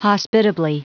Prononciation du mot hospitably en anglais (fichier audio)
Prononciation du mot : hospitably